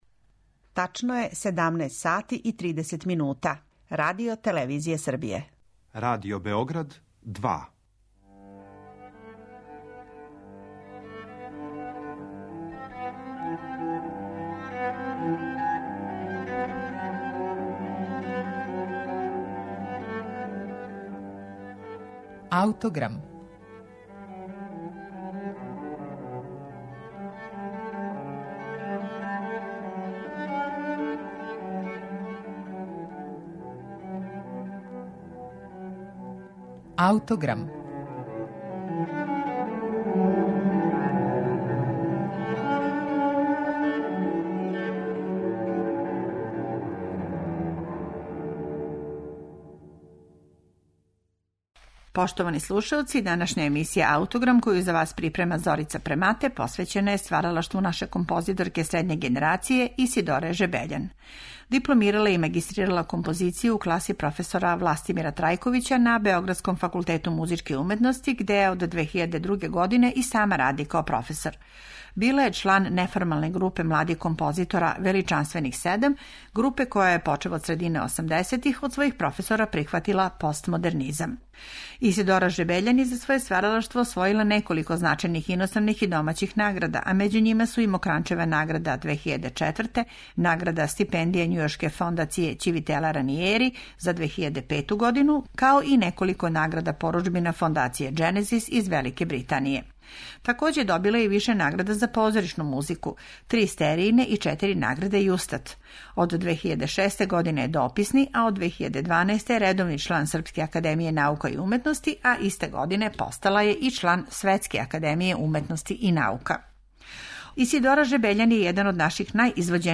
гудачки квартет
на београдској премијери